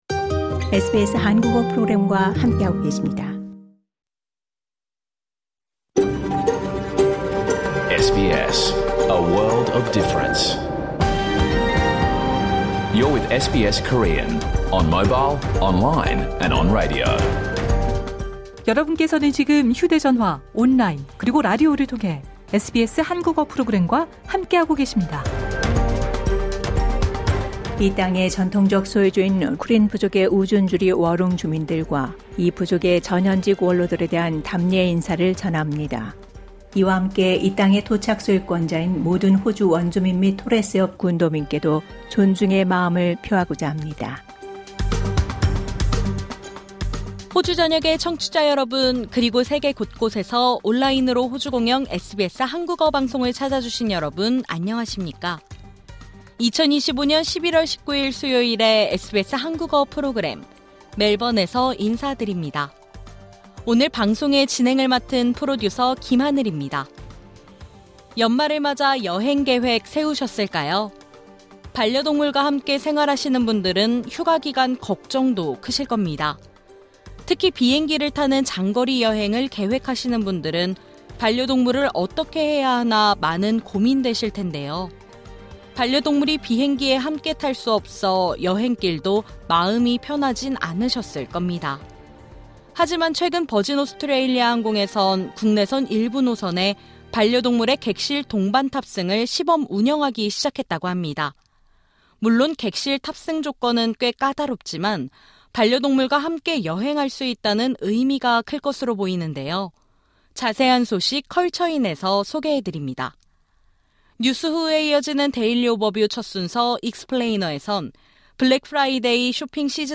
2025년 11월 19일 수요일에 방송된 SBS 한국어 프로그램 전체를 들으실 수 있습니다.